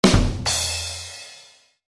Звук тарелок и барабанов: игра на тарелках, удары по барабану